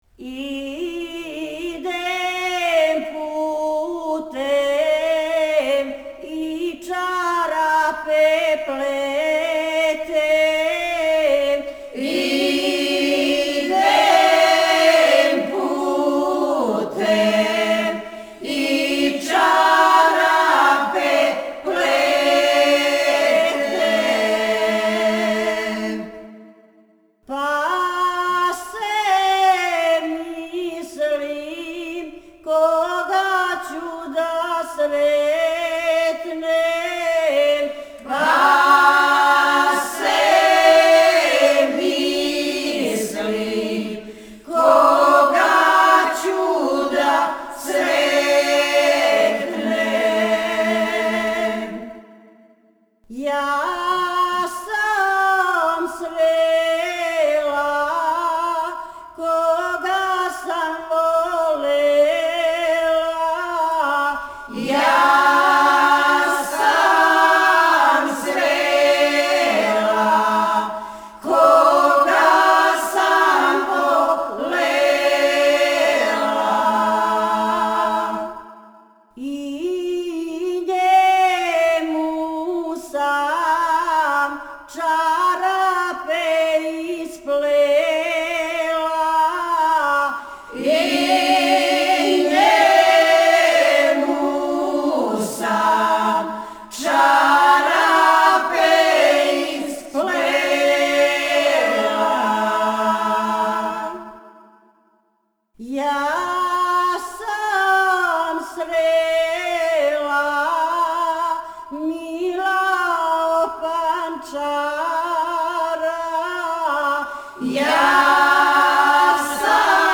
Група "Стеван Книћанин", Кнић
Порекло песме: Шумадија